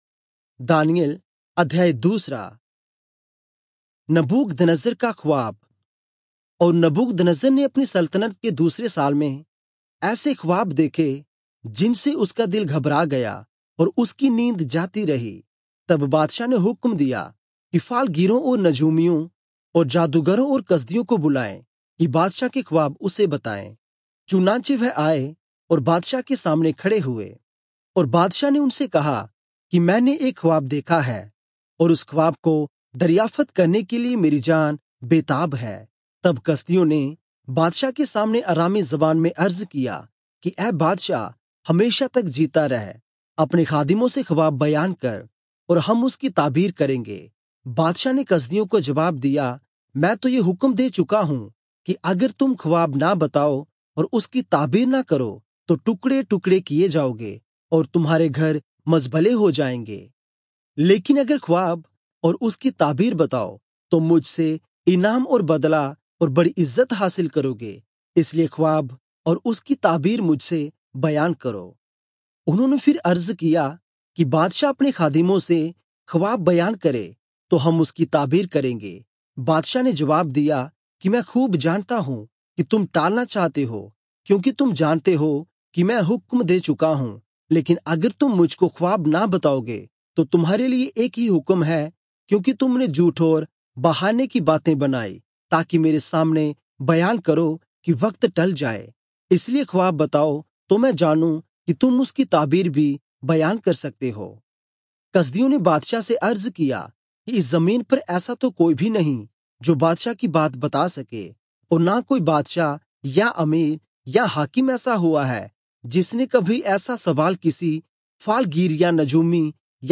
Urdu Audio Bible - Daniel 7 in Irvur bible version